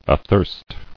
[a·thirst]